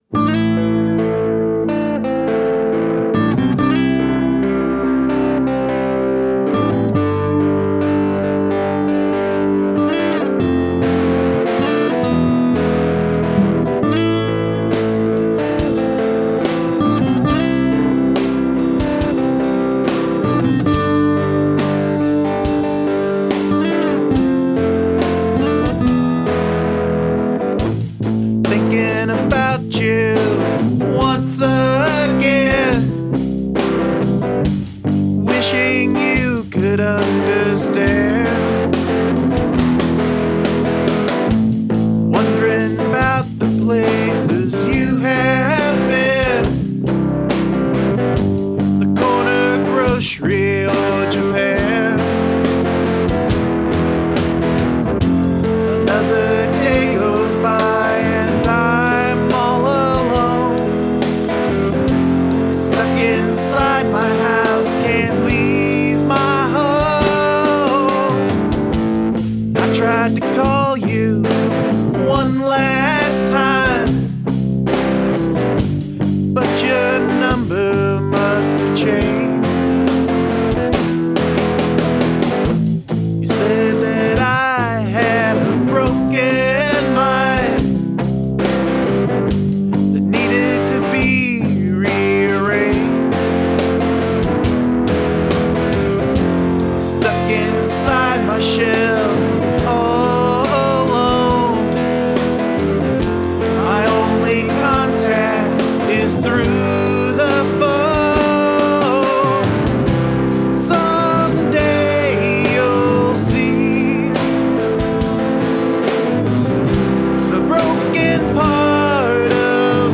Recorded on the BR-900
The chorus is a winner and I love the backing ooh.
The build to the big, catchy chorus is perfect.